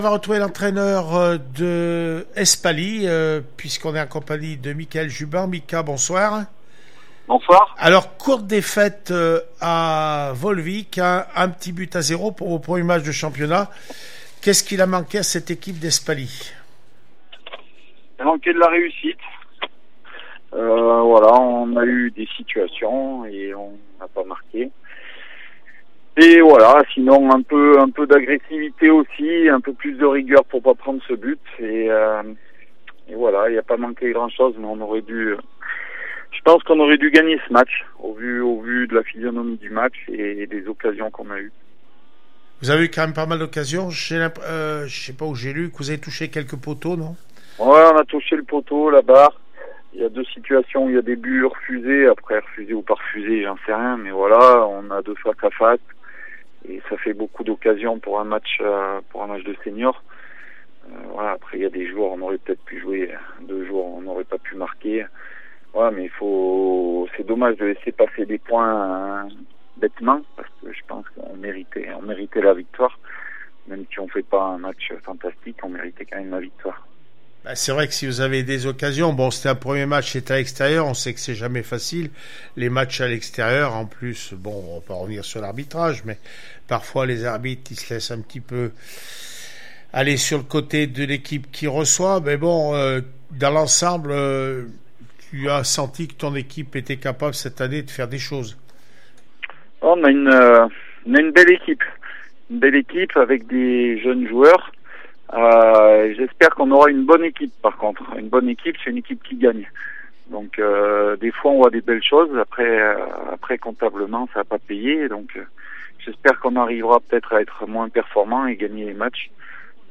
foot régional 1 volvic1-0 fc espaly réac après match 140920